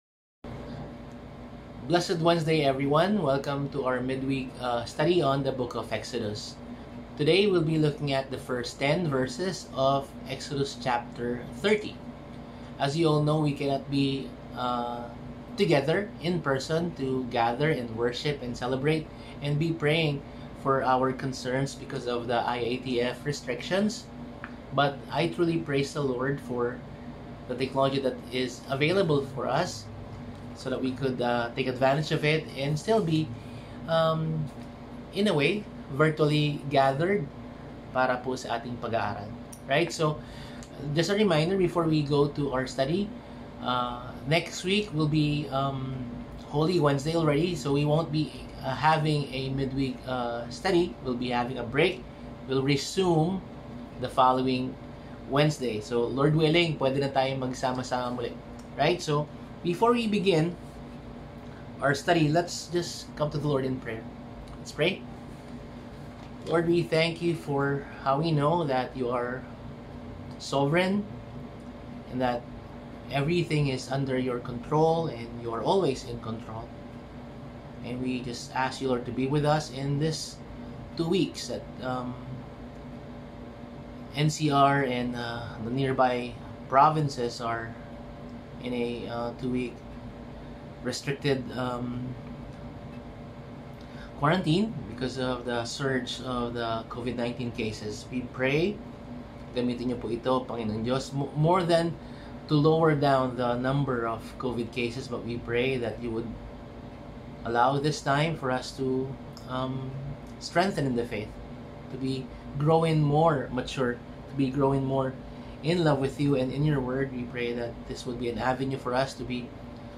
Service: Midweek